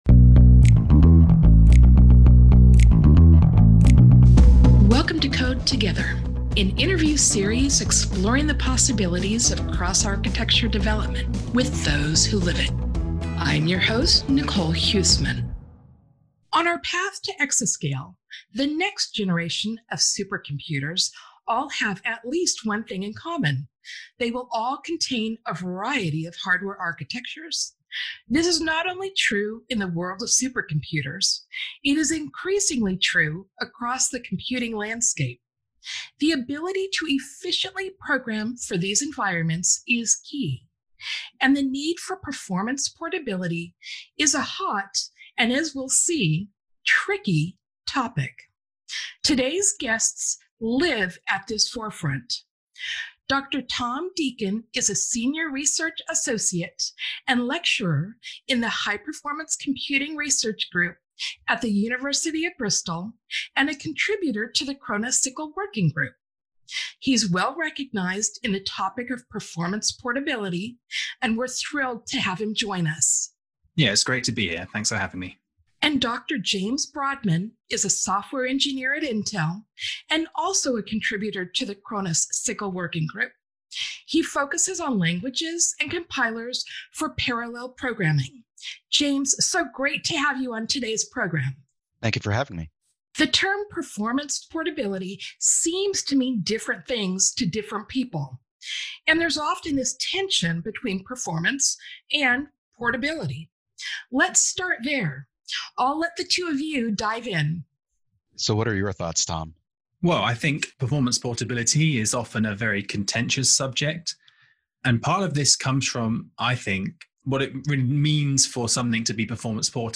Two senior engineers and Khronos SYCL Working Group contributors unpack the concept of performance portability: what it means and how to achieve it.